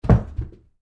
Звук упавшего предмета на ковер